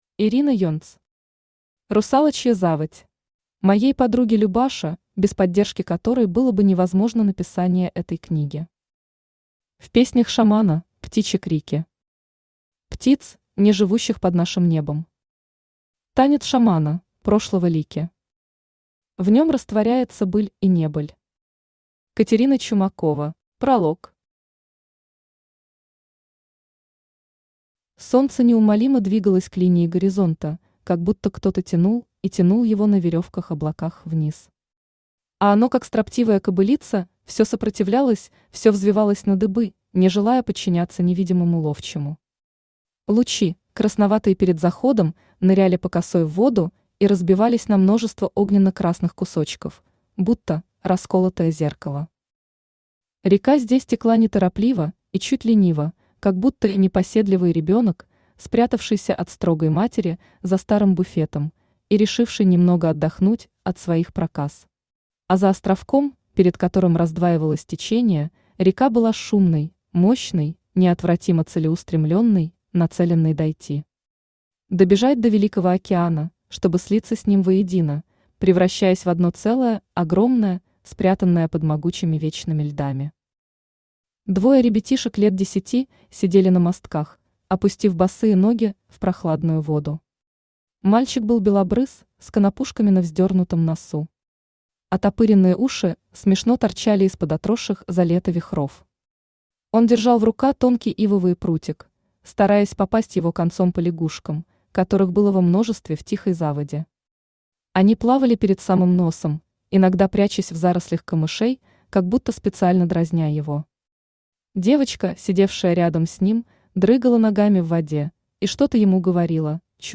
Аудиокнига Русалочья заводь | Библиотека аудиокниг
Aудиокнига Русалочья заводь Автор Ирина Юльевна Енц Читает аудиокнигу Авточтец ЛитРес.